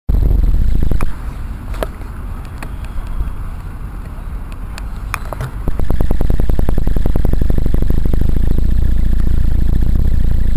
Brummen beim Laden von Actioncam mit DynamoZuUSB-Konverter
Damit habe ich meine Actioncam beim Fahren aufgeladen, während sie an war. Jetzt hat sich diese Störung beim Fahren ergeben. Ohne angeschlossenes Kabel gibt es sie nicht.